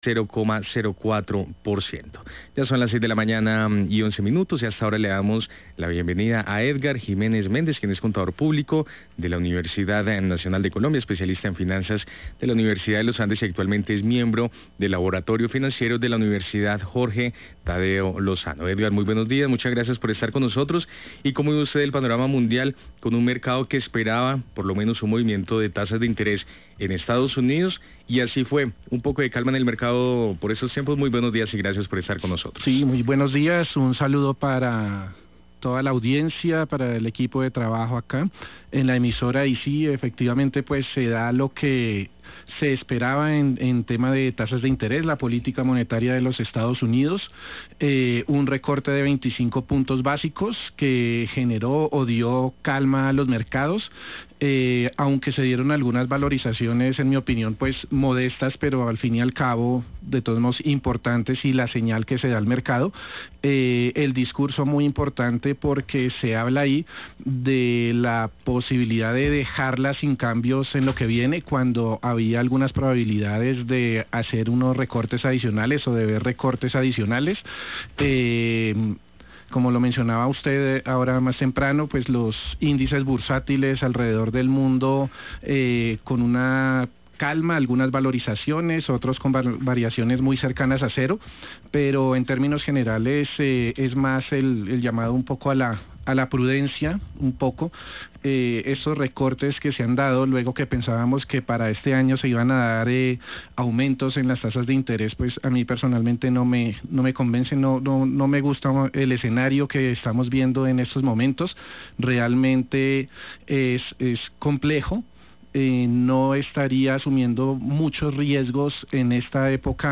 El escenario es realmente complejo y no estaría asumiendo muchos riesgos en esta época", indicó en el programa radial Primera Página.